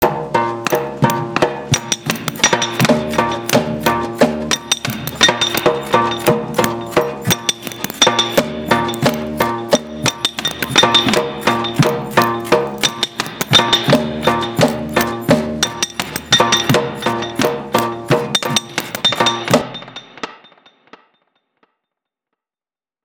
Everything I used is completely Ikea sourced except for a handful of rubber bands.
I decided to use my iphone to record since the internal mic is a little better than my computer.
Using 7 different Ikea made ‘instruments’ I layered 7 tracks to produce this (pretty terrible) beat.